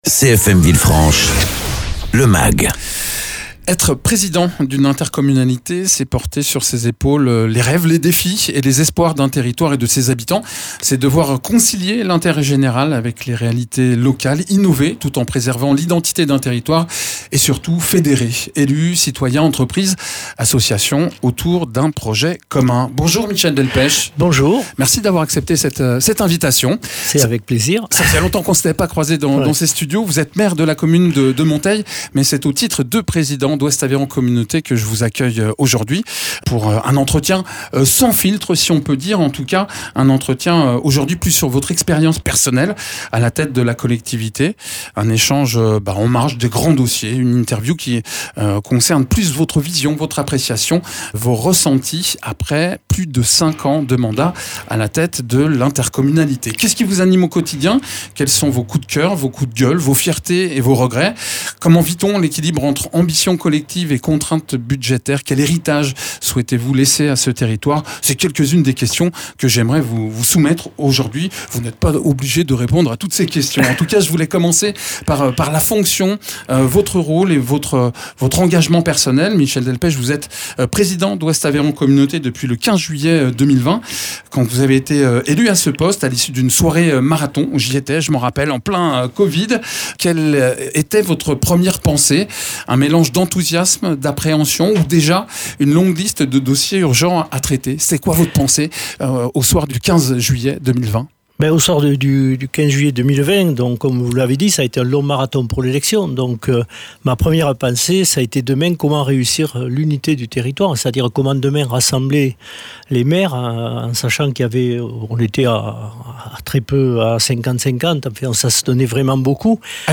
Interviews
Invité(s) : Michel Delpech, Président d’Ouest Aveyron Communauté, maire de Monteils,